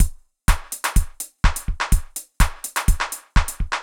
IBI Beat - Mix 9.wav